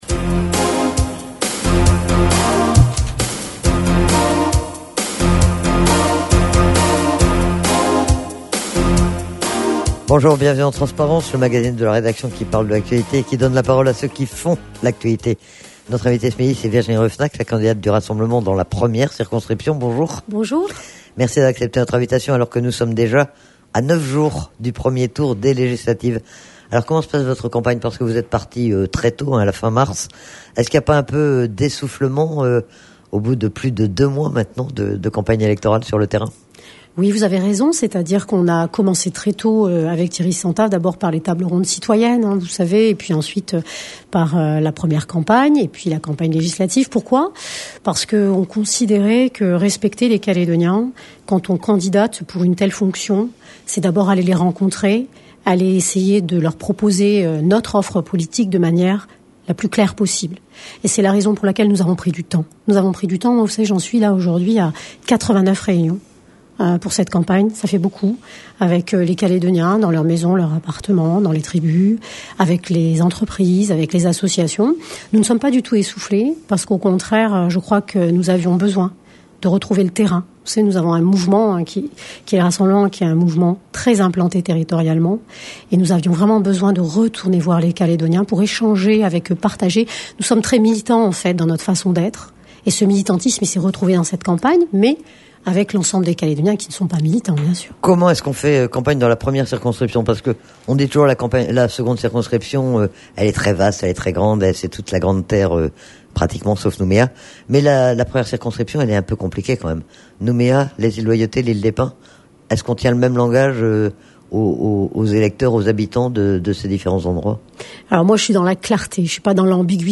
Elle était interrogée sur les raisons de sa candidature, sur ses motivations et son programme mais aussi le contexte de cette élection.